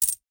sell_sound.mp3